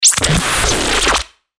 FX-1542-BREAKER